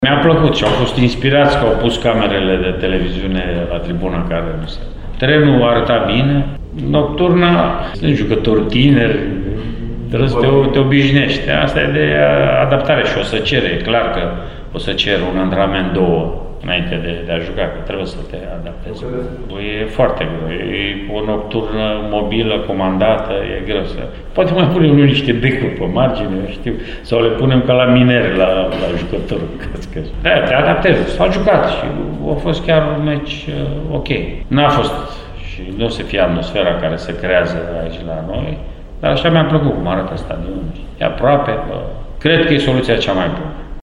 Rednic a vorbit, din nou, despre decizia mutării la Oradea, mai ales în contextul partidei disputate, miercuri, de FC Bihor, în compania celor de la FCSB, în nocturnă: